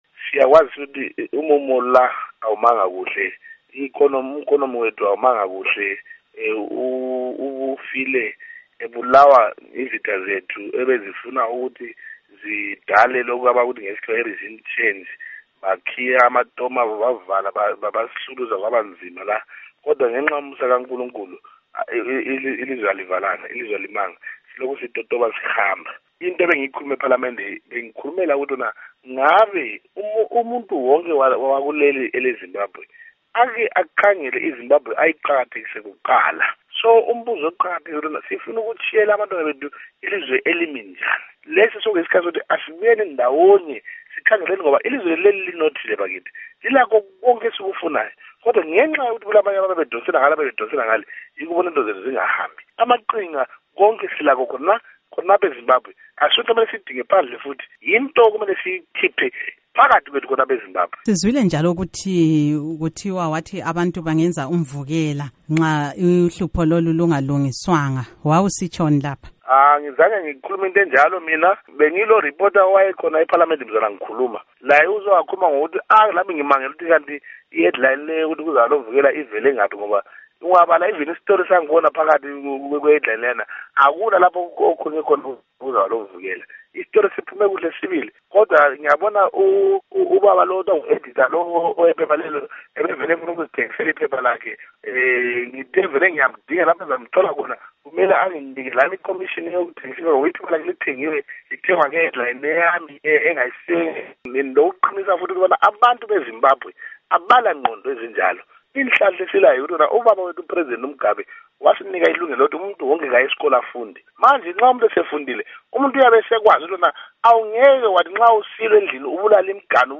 Ingxoxo LoMnu.